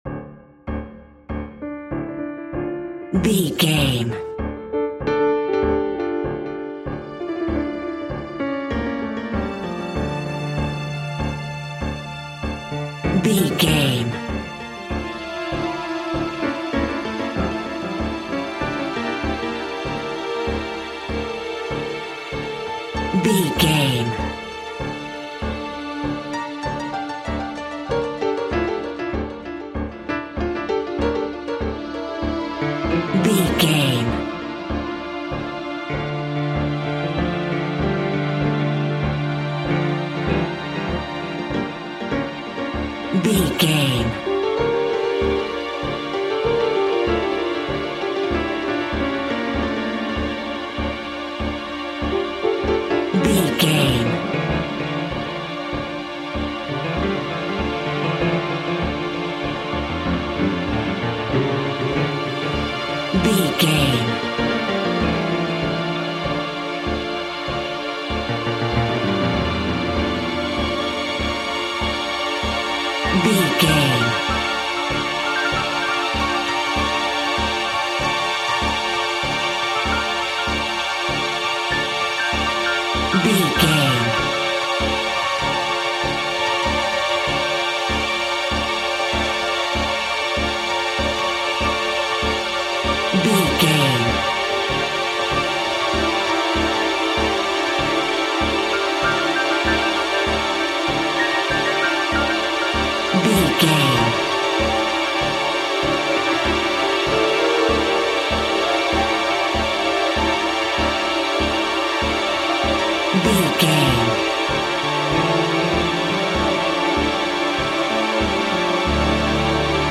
In-crescendo
Aeolian/Minor
Fast
tension
ominous
eerie
strings
synth
ambience
pads